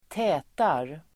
Uttal: [²t'ä:tar]